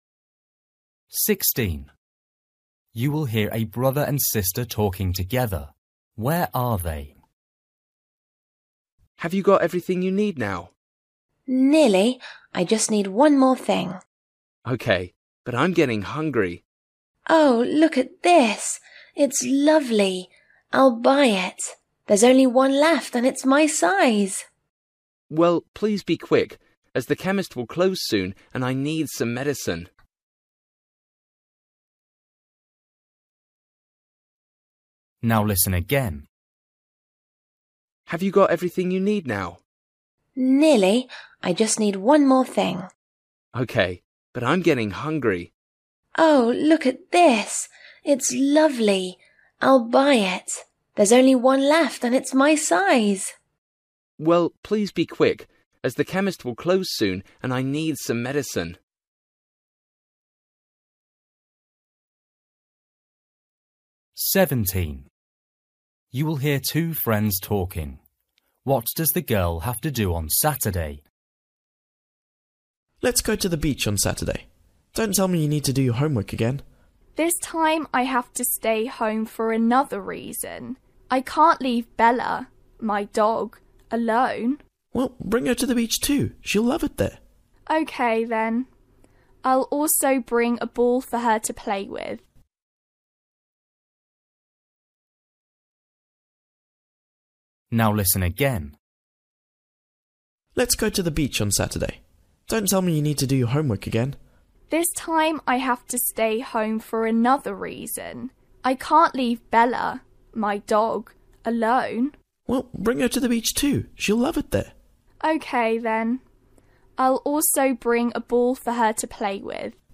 Listening: everyday short conversations
16   You will hear a brother and sister talking together. Where are they?
17   You will hear two friends talking. What does the girl have to do on Saturday?
19   You will hear two classmates talking. Why is the girl upset?